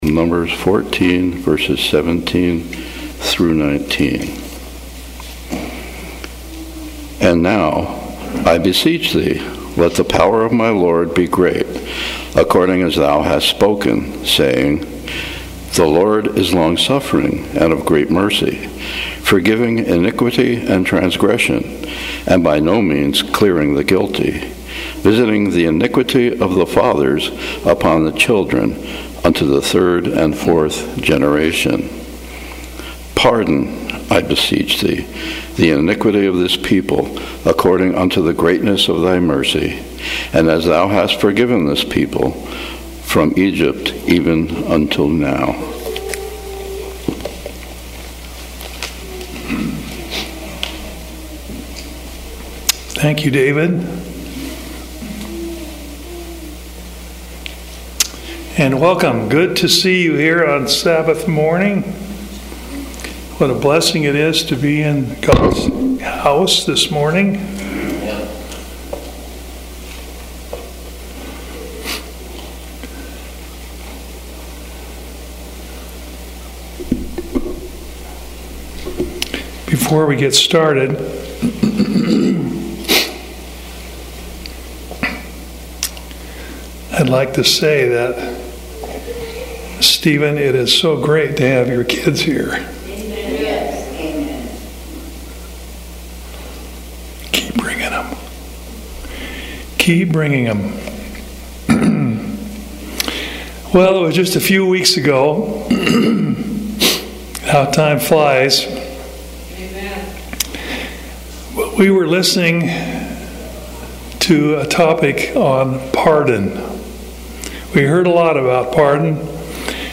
Seventh-day Adventist Church, Sutherlin Oregon
Sermons and Talks 2024